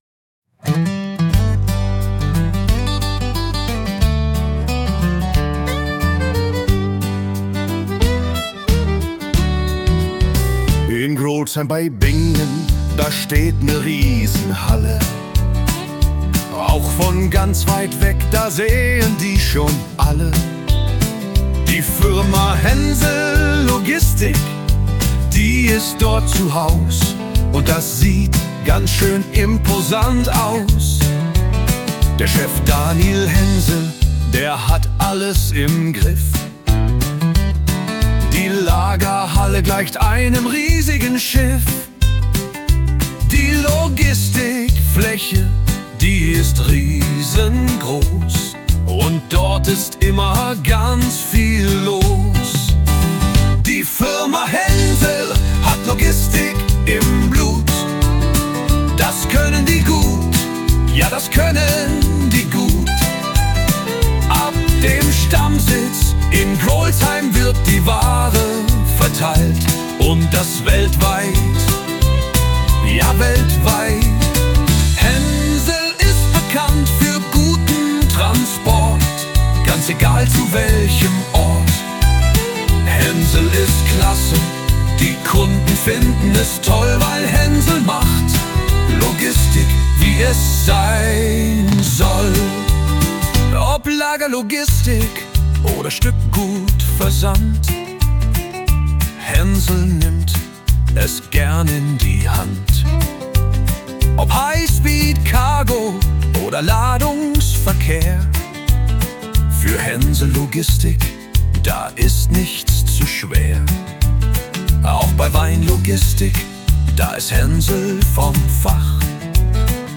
Mit Hilfe von KI erstellt.